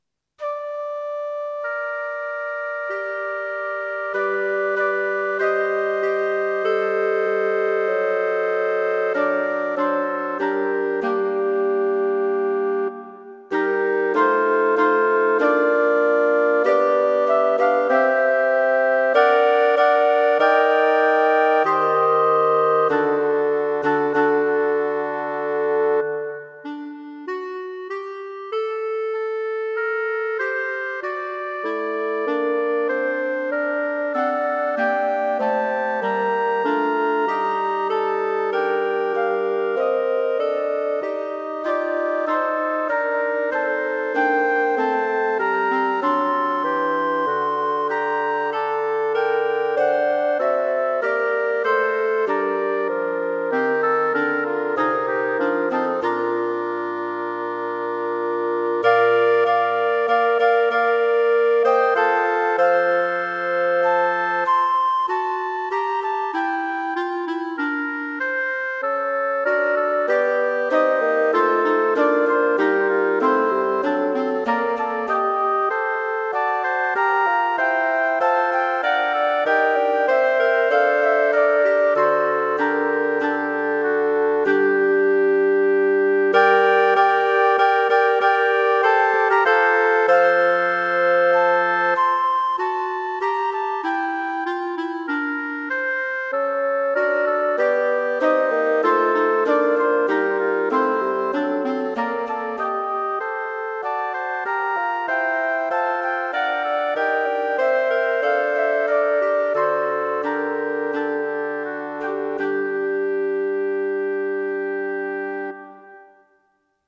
Wind Quartet for Concert performance